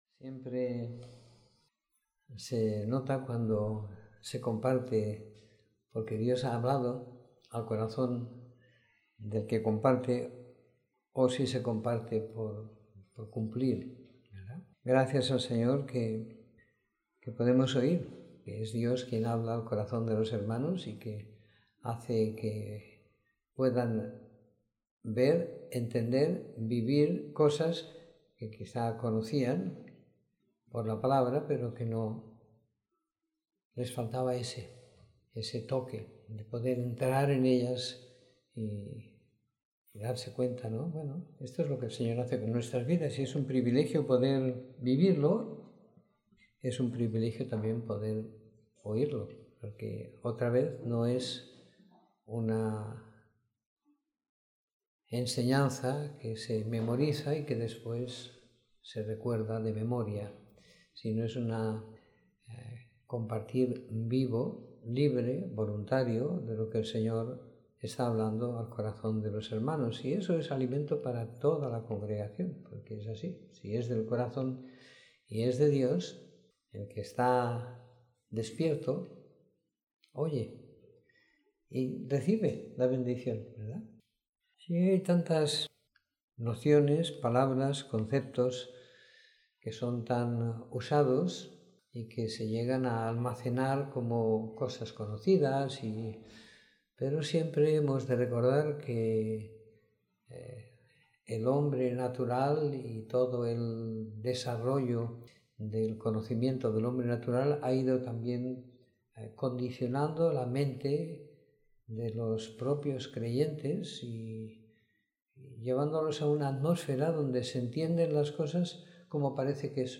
Reunión semanal de compartir la Palabra y la Vida.